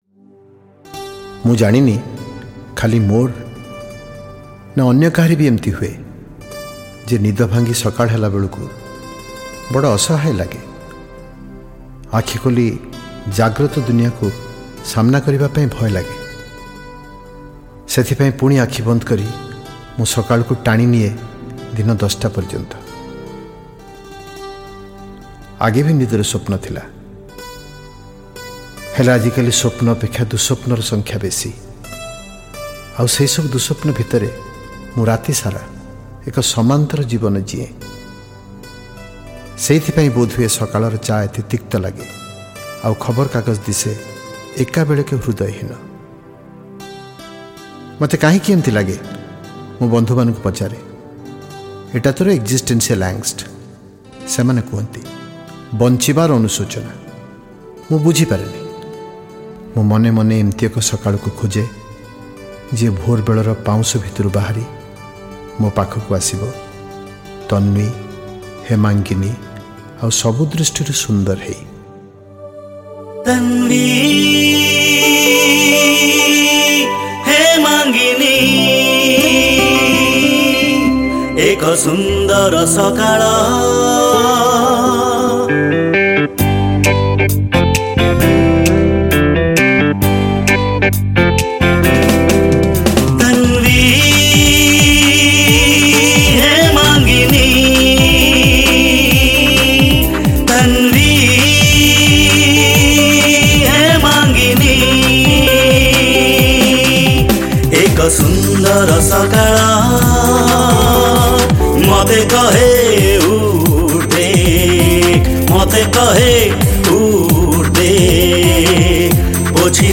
Category: Blue Mood